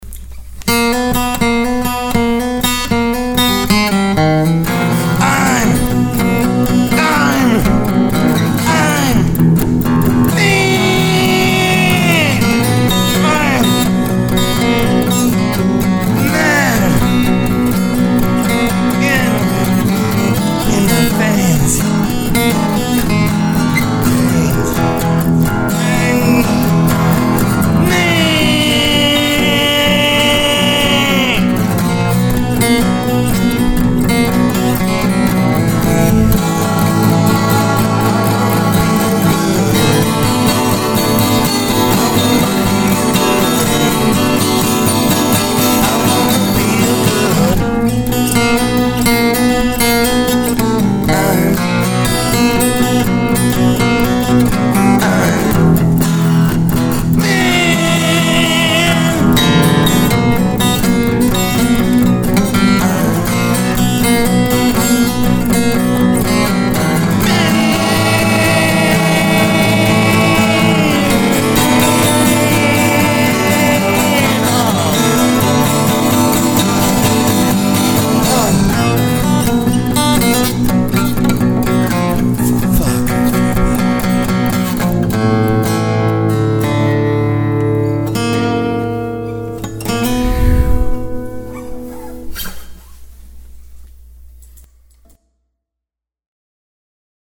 the Mean, Acoustic Punk Song
I screamed this creation, all by my wittle self.